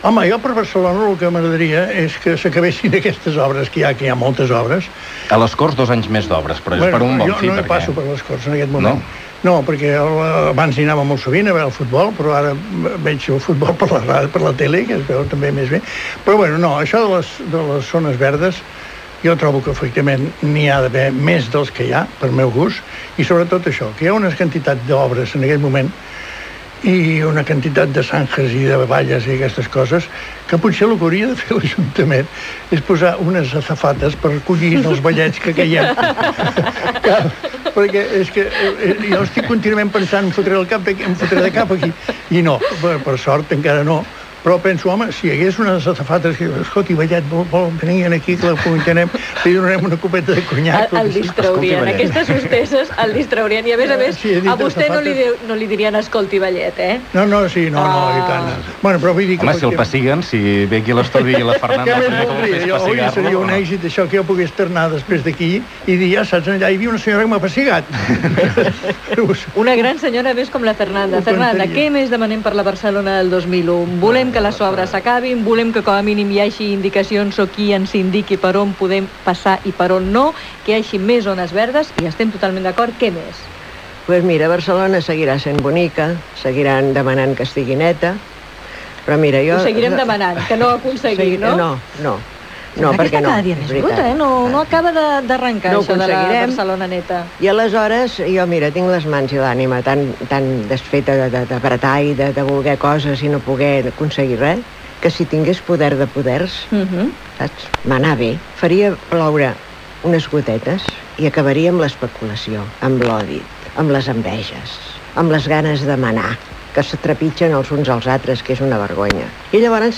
Tertúlia de veterans